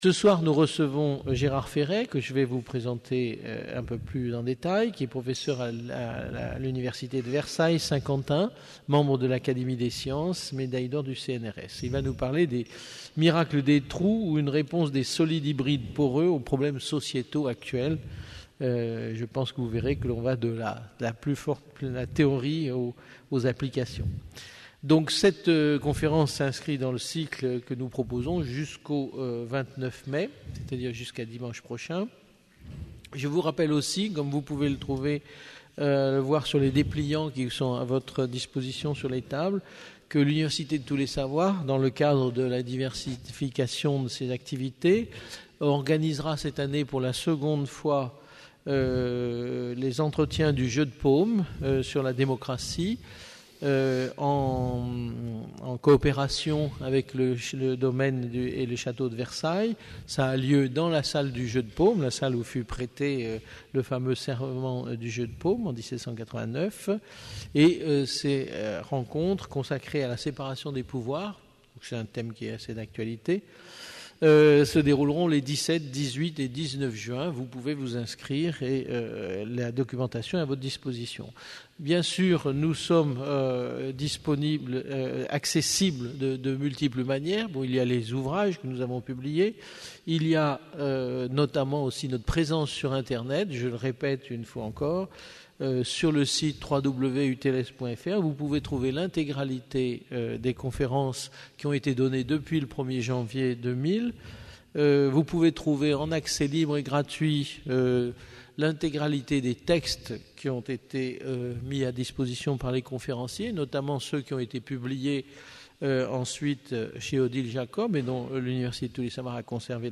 Une conférence UTLS du cycle : « La Chimie partout » du 21 au 29 mai 2011 à 18h30